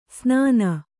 ♪ snāna